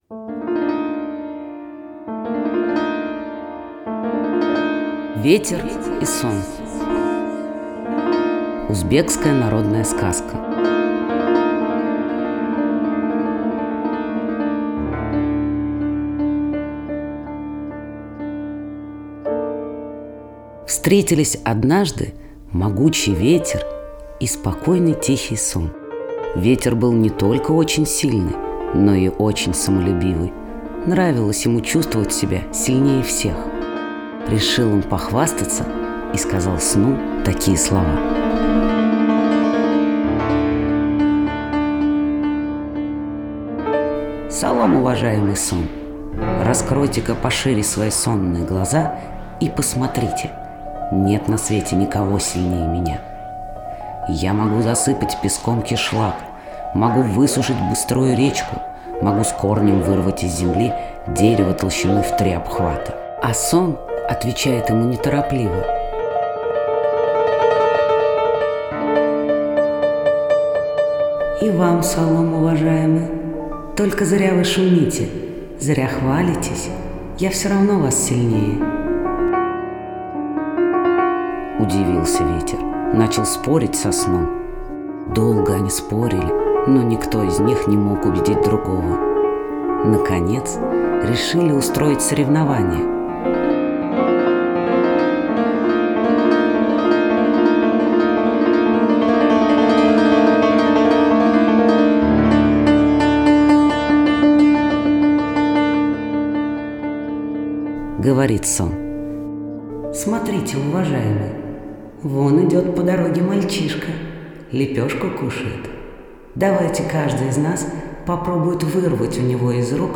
Ветер и Сон - узбекская аудиосказка - слушать онлайн